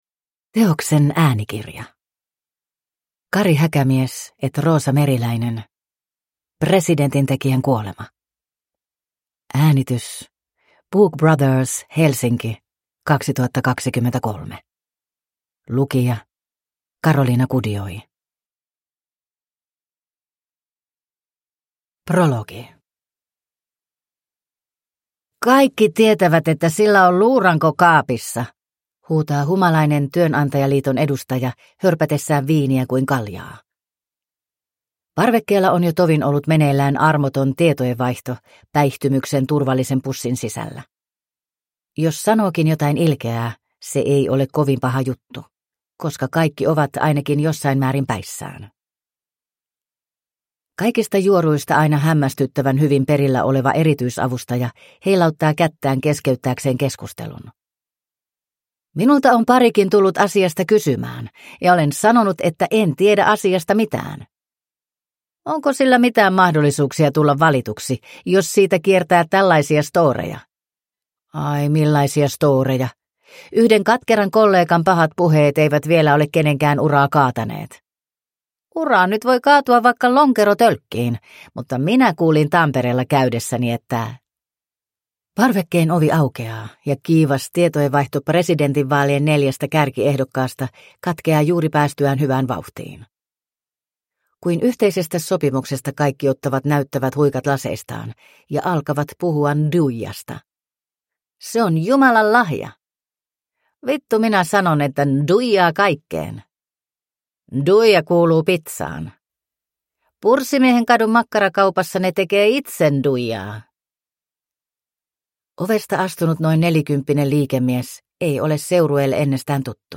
Presidentintekijän kuolema – Ljudbok